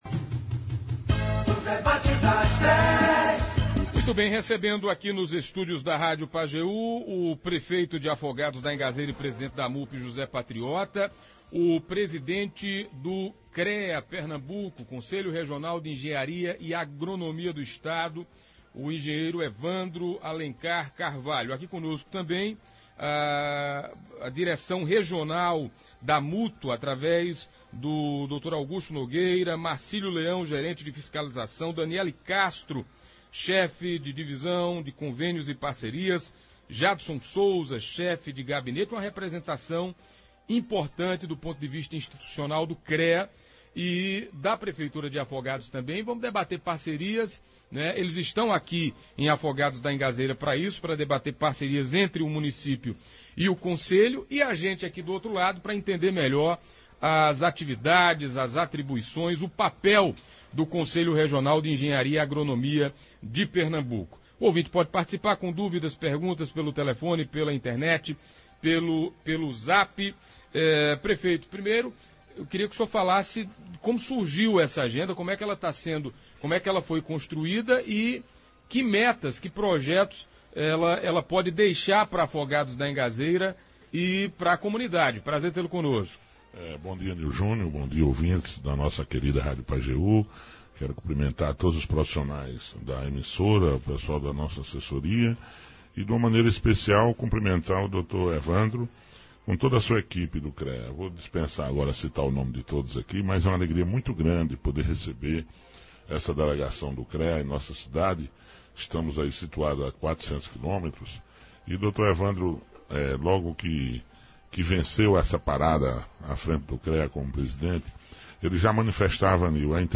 E em visita a Afogados na manhã desta sexta-feira (12), uma comitiva chefiada pelo presidente da Autarquia, Evandro Alencar, junto com o prefeito de Afogados da Ingazeira e presidente da Amupe José Patriota, participaram do Debate das Dez da Rádio Pajeú.
Um protocolo de intenções foi assinado nos estúdios da Rádio Pajeú. Dentre as ações, o CREA pretende criar uma Inspetoria em Afogados da Ingazeira.